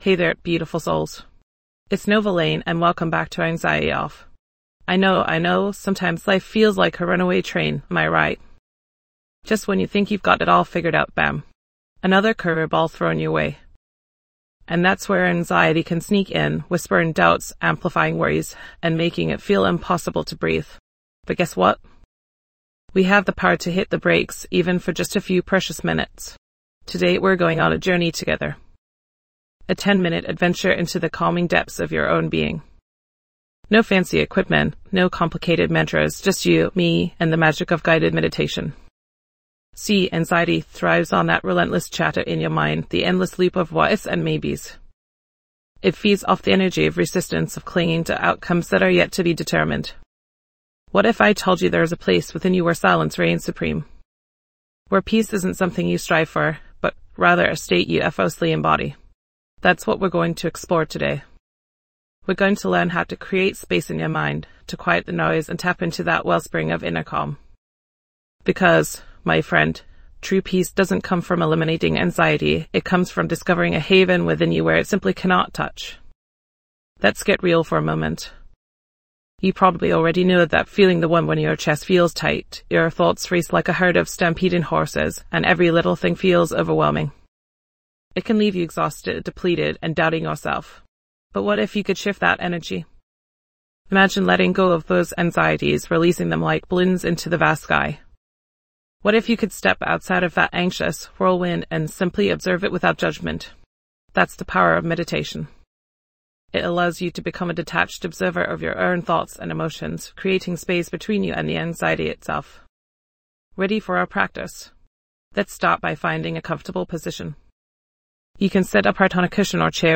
Dive deep into a 10-minute guided meditation designed to help you conquer anxiety and stress, leading to inner peace and tranquility.